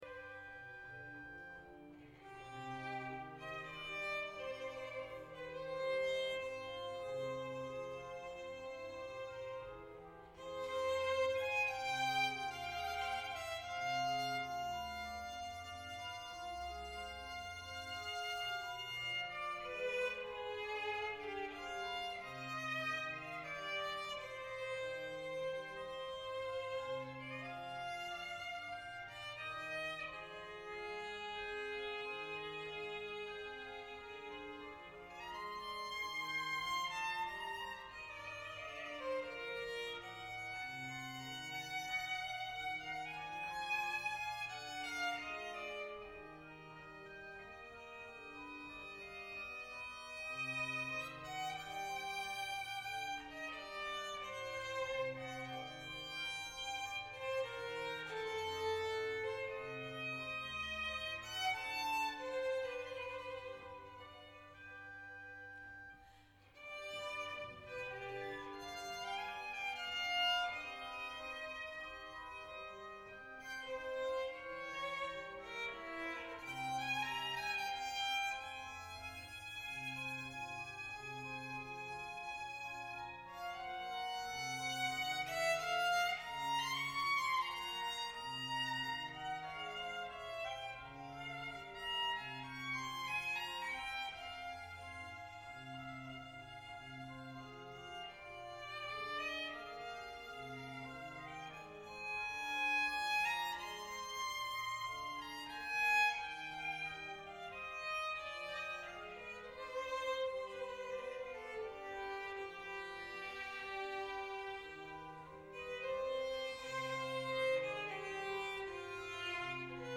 Klassische Kirchenkonzerte
Der Programmschwerpunkt ihrer Konzerte liegt auf Barockmusik, insbesondere von J.S. Bach und G.F. Händel, für Violine und Orgel sowie auf gesungenen Gebeten und Werken anderer Komponisten wie F. Mendelssohn, W.A. Mozart und J. Rheinberger.